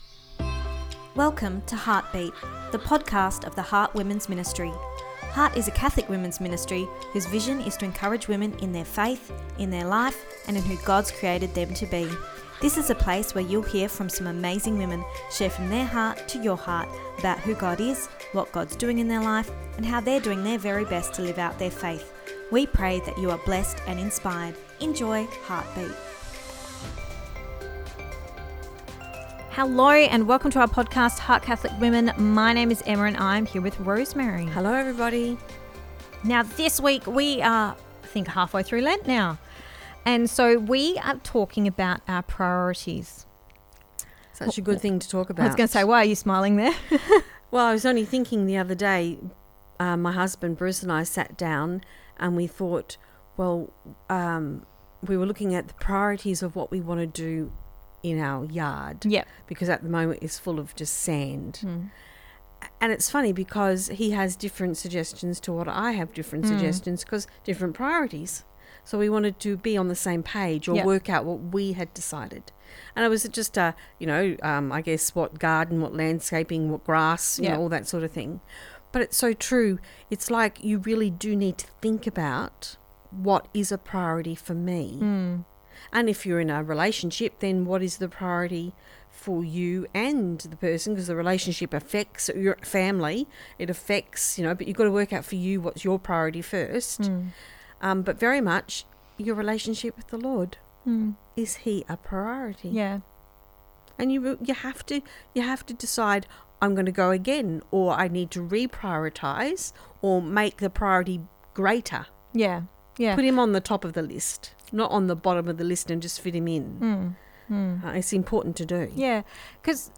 Ep289 Pt2 (Our Chat) – Lent: What Are Your Priorities?